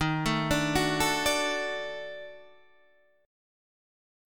D# Minor Major 7th Flat 5th